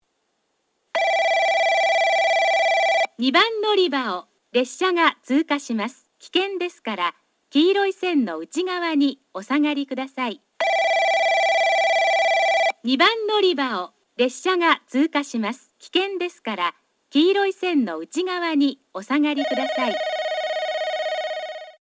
2番のりば通過放送
放送はJACROS簡易詳細型です。
スピーカーは0，1がカンノボックス型、2，3がカンノや円型ワイドホーン、TOAラッパ型です。